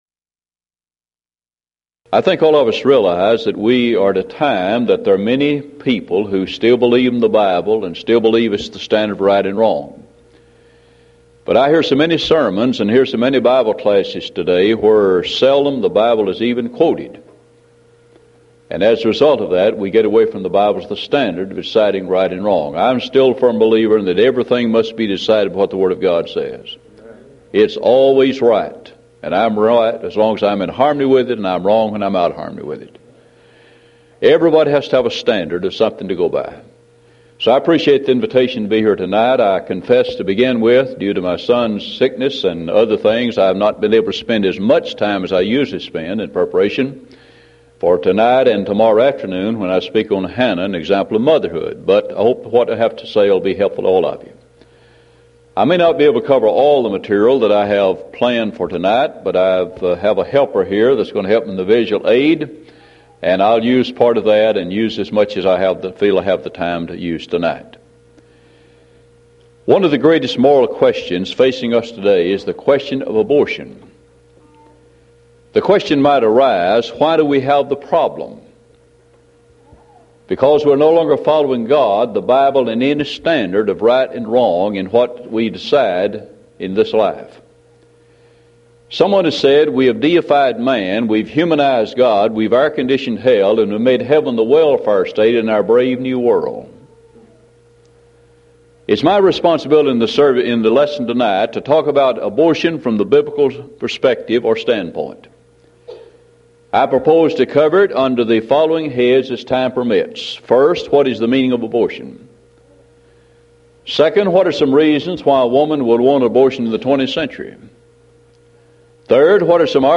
Event: 1993 Mid-West Lectures